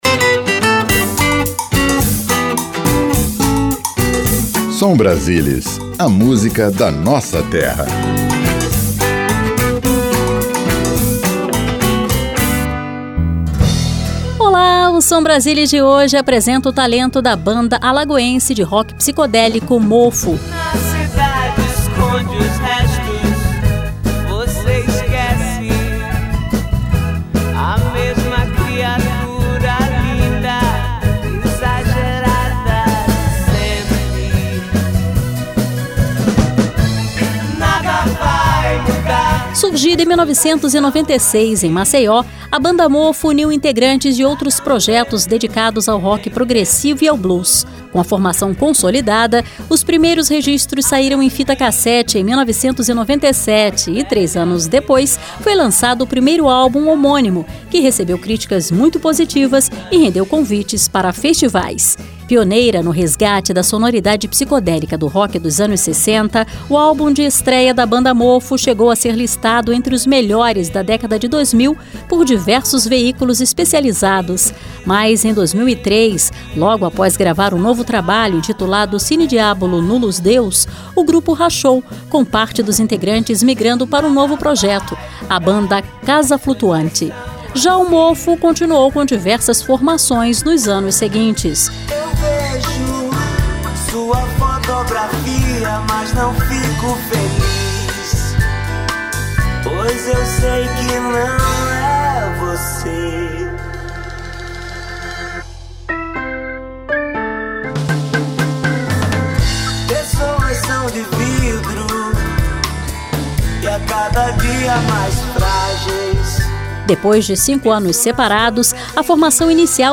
Programete musical que apresenta artistas de cada estado da federação.